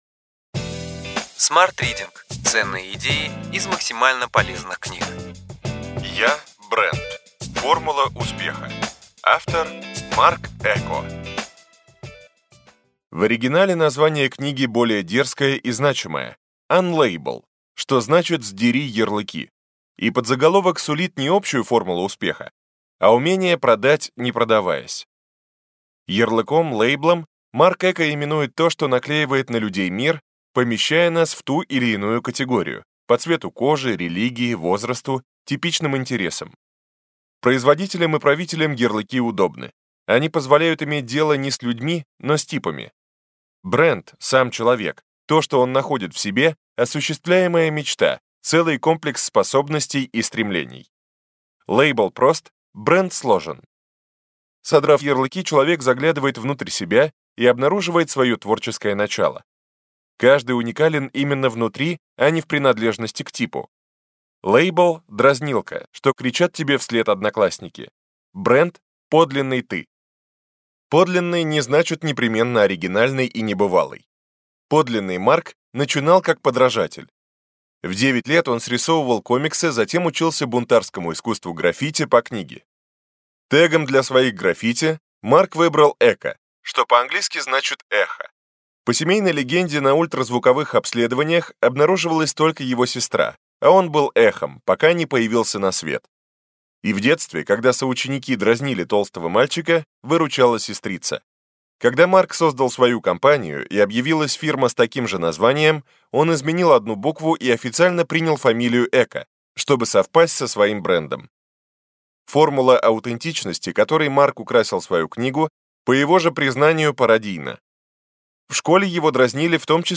Аудиокнига Ключевые идеи книги: Я – бренд. Формула успеха.